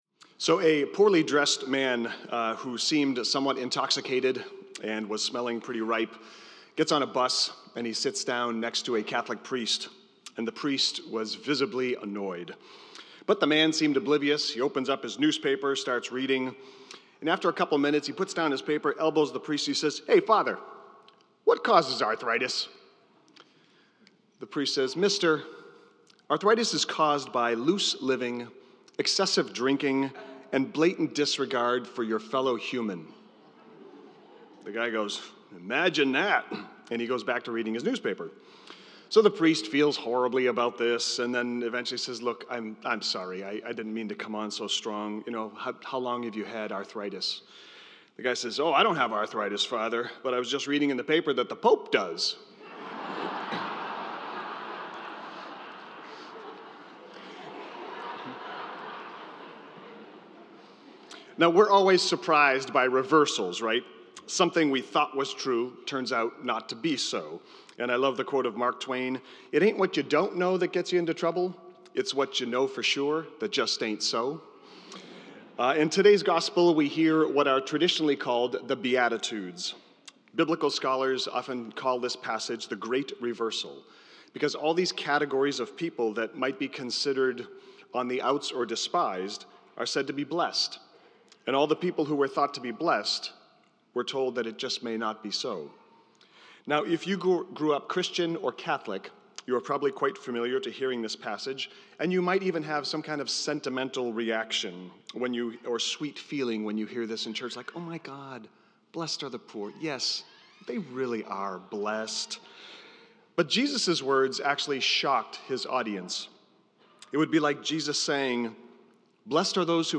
Homily Transcript: So a poorly dressed man who seemed somewhat intoxicated and was smelling pretty ripe gets on a bus and sits down next to a Catholic priest and the priest was visibly annoyed.